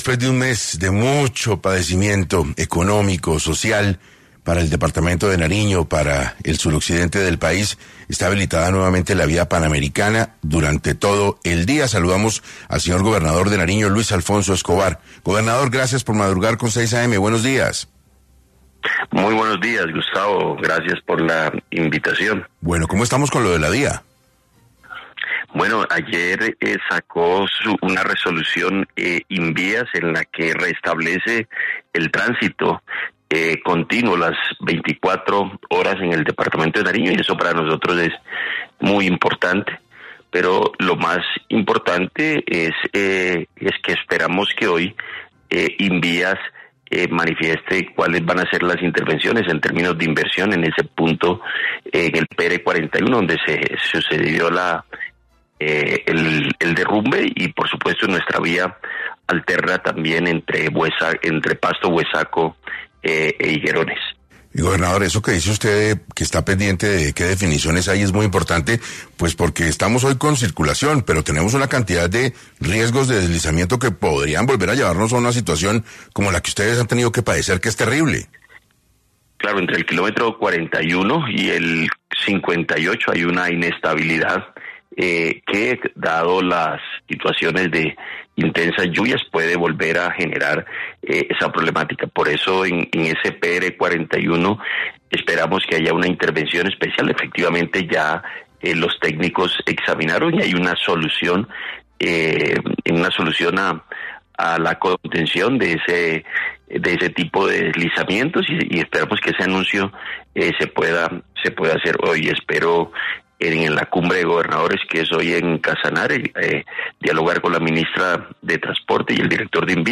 En 6AM estuvo Luis Alfonso Escobar, Gobernador de Nariño, quien habló sobre la reapertura de Gobernador de Nariño vía Panamericana y cuáles fueron las perdidas que su cierre representó al departamento.
En entrevista para 6AM, el gobernador de Nariño, Luis Alfonso Escobar, se pronunció ante el hecho, habló de las graves consecuencias que el cierre deja para el departamento y sobre cómo se pueden prevenir este tipo de tragedias.